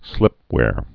(slĭpwâr)